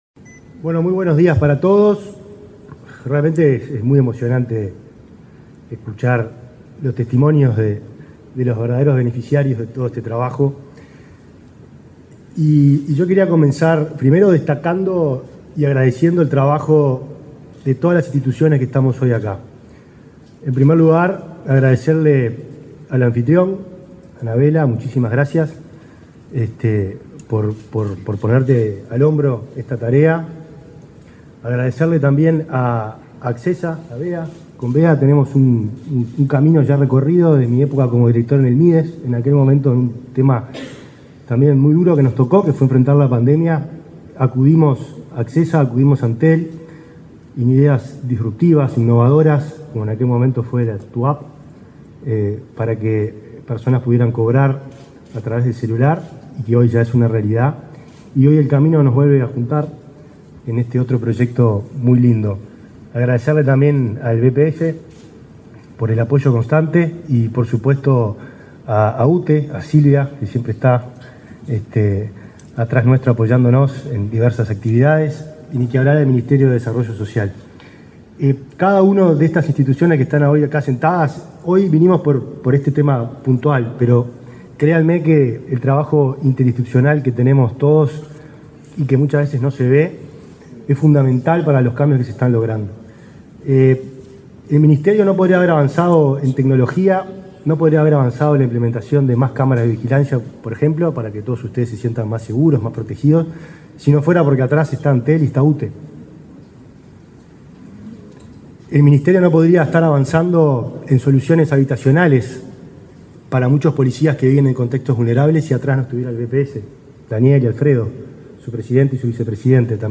Palabras de autoridades en lanzamiento de centro de atención telefónica en lengua de señas
Palabras de autoridades en lanzamiento de centro de atención telefónica en lengua de señas 21/10/2024 Compartir Facebook X Copiar enlace WhatsApp LinkedIn Este lunes 21 en la Torre de las Telecomunicaciones, el ministro del Interior, Nicolás Martinelli; su par de Desarrollo Social, Alejandro Sciarra; el vicepresidente del BPS, Daniel Graffigna; la presidenta de la UTE, Silvia Emaldi, y la titular de Antel, Anabela Suburú, participaron en el acto de lanzamiento de un centro de atención telefónica en lengua de señas.